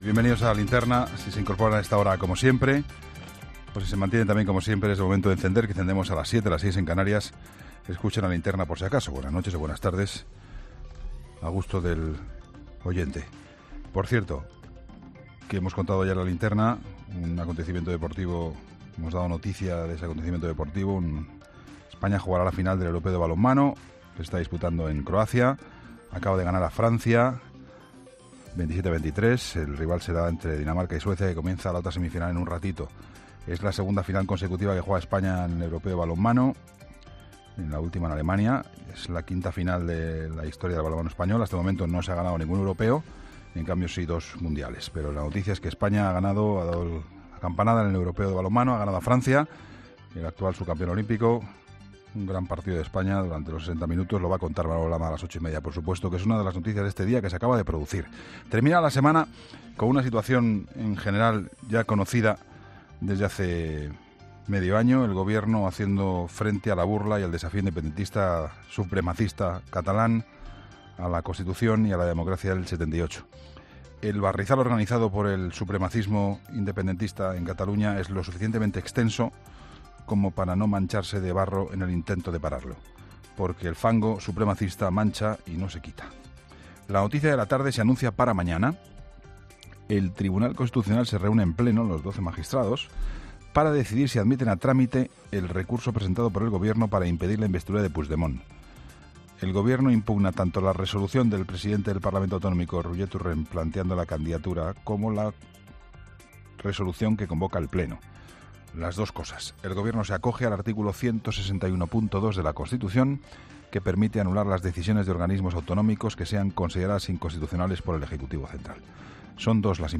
La crónica de Juan Pablo Colmenarejo
EN 'LA LINTERNA'